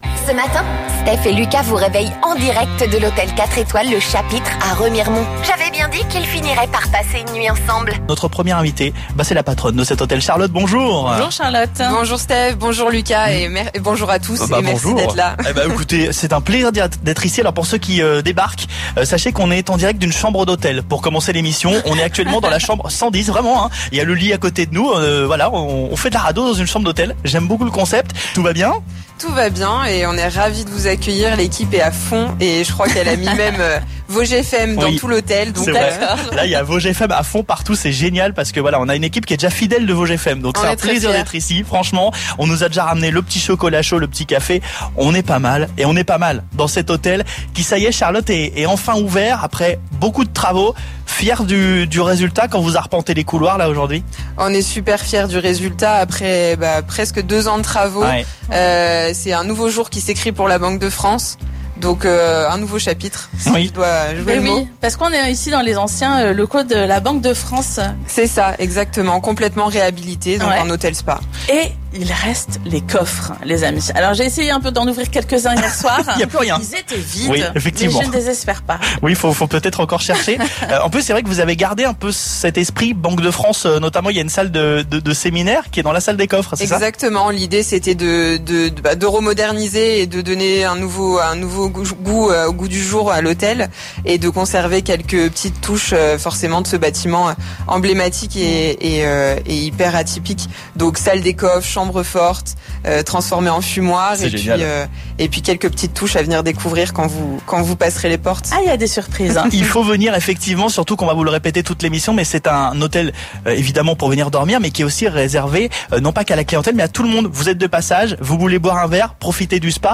en direct depuis le nouvel hôtel et spa 4 étoiles Le Chapitre à Remiremont. Ouvert depuis quelques jours à peine, l'établissement s'est installé dans l'ancienne Banque de France.